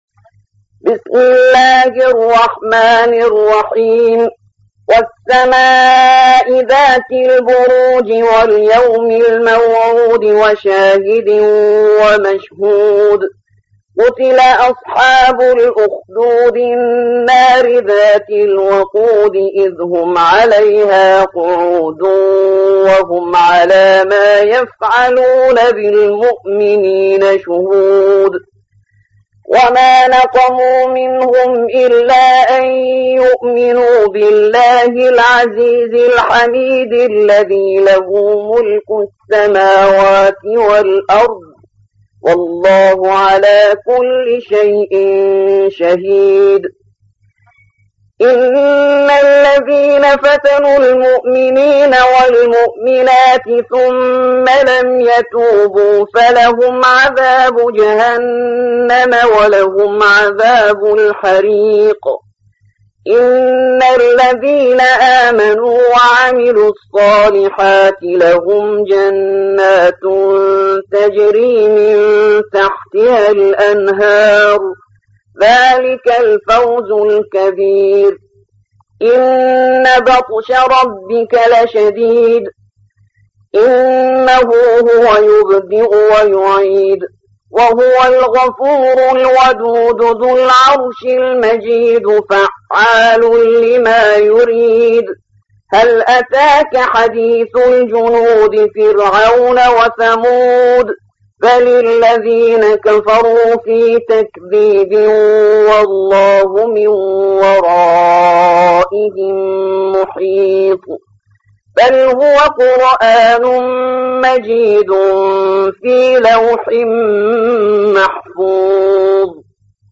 موقع يا حسين : القرآن الكريم 85.